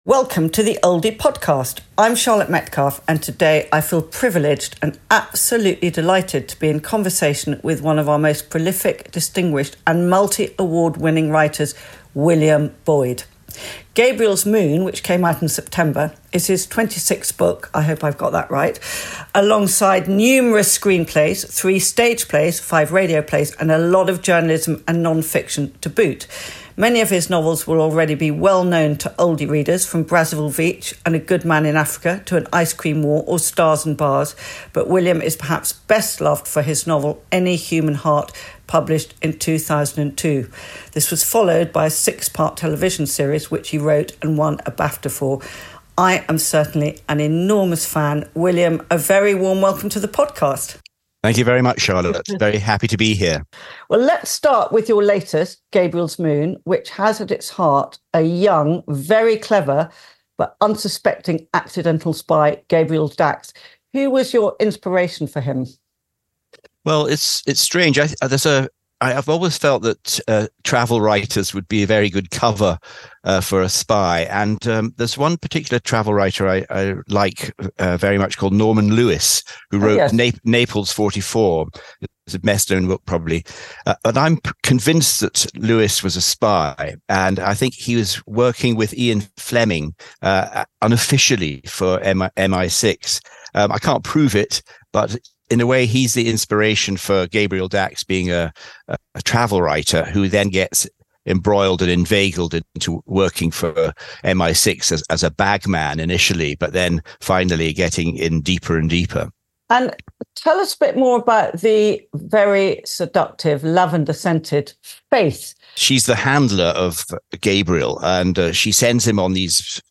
Oldie Podcast - William Boyd in conversation